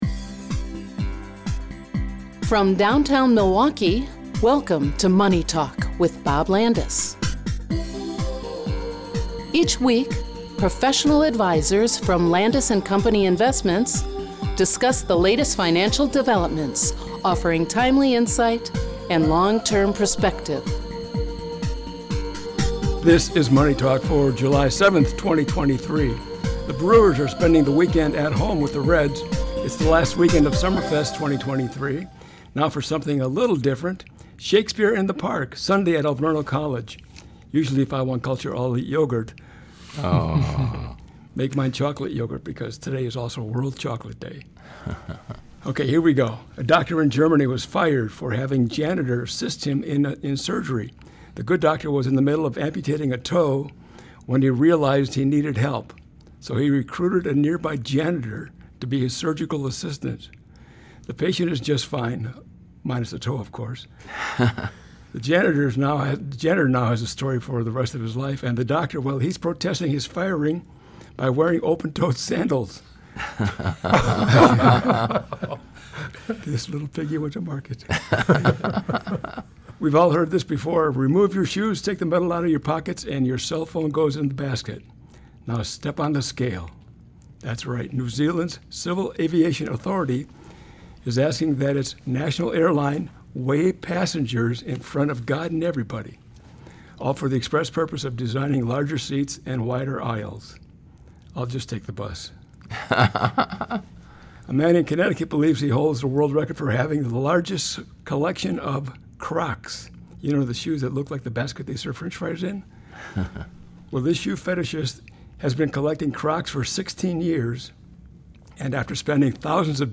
Advisors on This Week’s Show